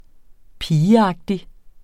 Udtale [ ˈpiːəˌɑgdi ]